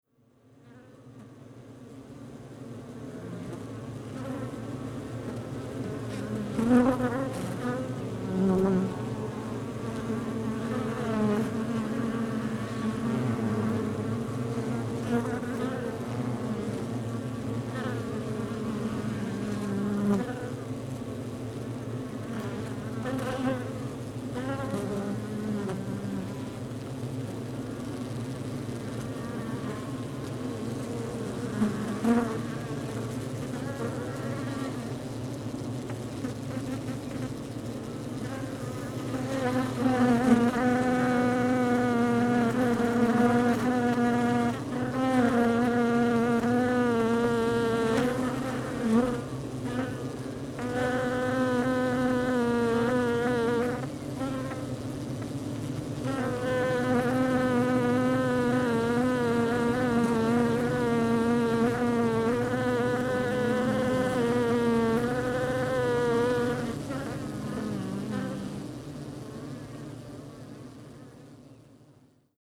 Abeilles et Ruches
Quelle fut ma surprise d'entendre les abeilles d'aussi près, des ronflements d'ailes sur la tête du micro, des va-et-vient incessants de ces ouvrières inlassables, on y entend les abeilles qui décollent de la ruche et celles qui "aruchissent" si j'ose dire. En effet, l'abeille qui part démarre ses ailes et son bruit de vol s'éloigne. L'abeille qui arrive à la ruche émet un son qui s'approche et cesse brutalement à l'atterrissage. La stéréo est inouïe, c'est comme si les abeilles passaient dans la tête d'un côté à l'autre (au casque par exemple), très impressionnant.
Tout près des ruches, à l'extérieur, l'on peut même imaginer par moments une course de Formule 1 tellement leurs vols y ressemblent.
Tous les sons sont naturels, on perçoit parfois le vent dans les branches d'arbres alentour, des oiseaux lointains, et y compris par moments un cloche d'église située à moins de 2 Km de la ruche, qui connaît le son des cloches d'églises entendues depuis l'intérieur d'une ruche, mmmhhh... ?
Demo 05 Ruche Exterieur 2.mp3 (320 Kbps -VBR-, environ 2,8 Mo, 01:12)
Demo 05 Ruche Exterieur 2.mp3